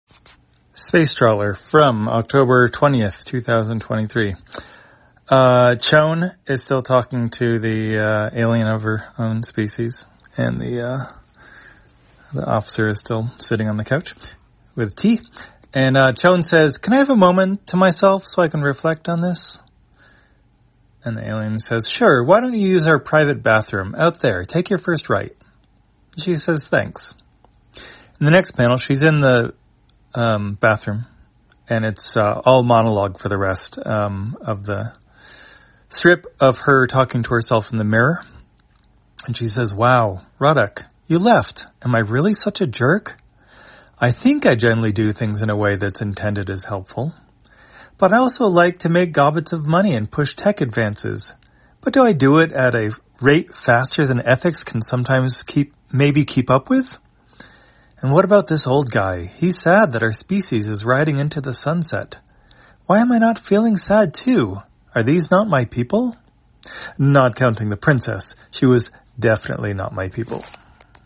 Spacetrawler, audio version For the blind or visually impaired, October 20, 2023.